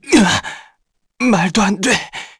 Neraxis-Vox_Dead_kr.wav